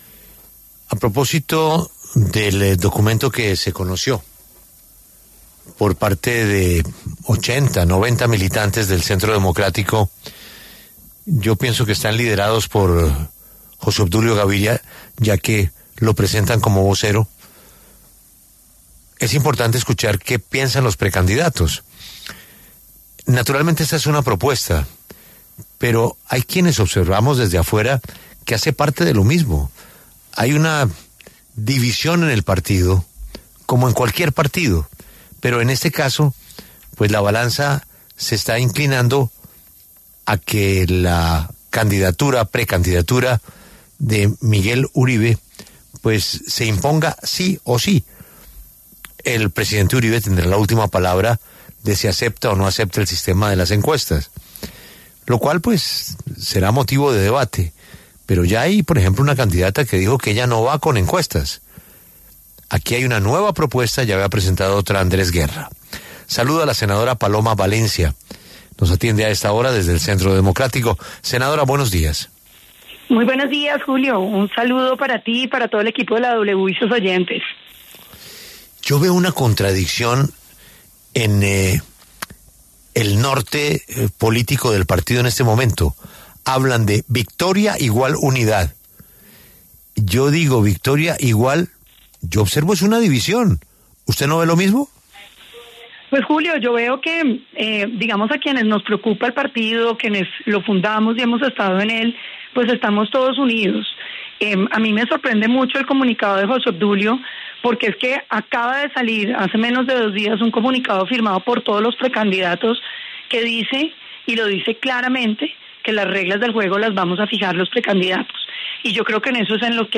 La senadora y precandidata presidencial Paloma Valencia pasó por los micrófonos de La W. Habló sobre la carta firmada por José Obdulio Gaviria y otros 80 militantes para que el candidato único de la colectividad se elija con una seguidilla de encuestas.